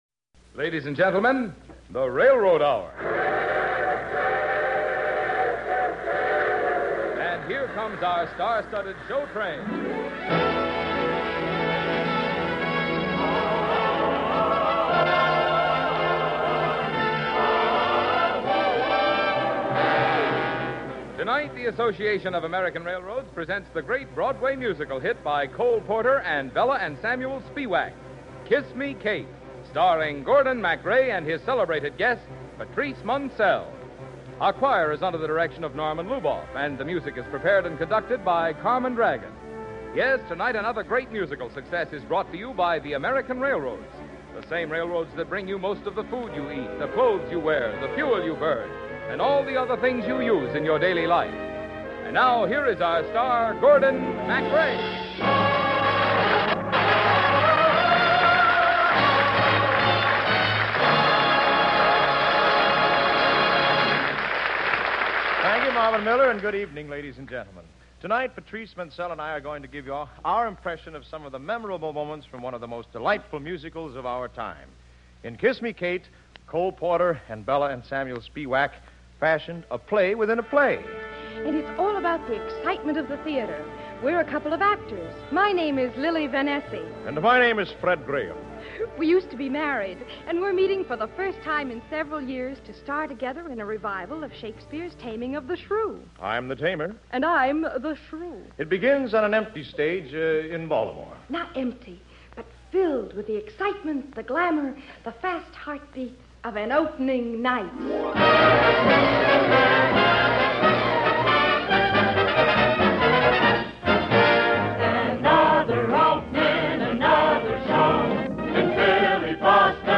musical dramas and comedies
Host and Leading Man
hosted each episode and played the leading male roles